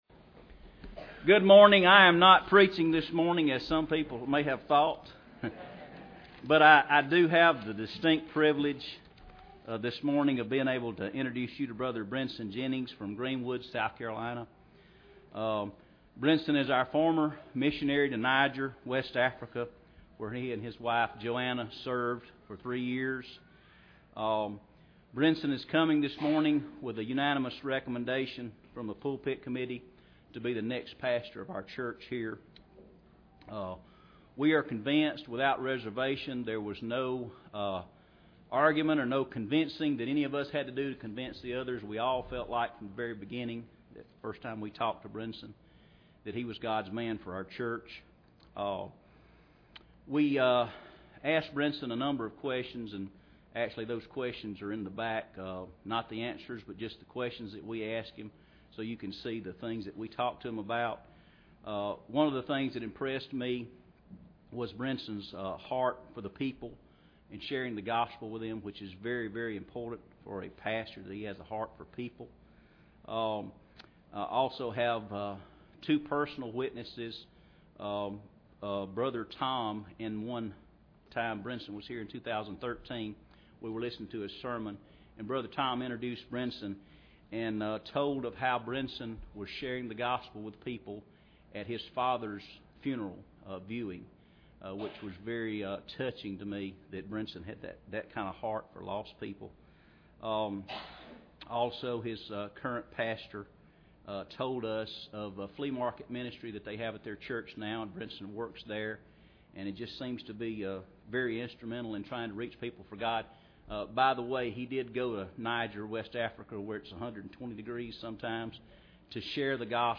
Ephesians 6:1-4 Service Type: Sunday Morning Bible Text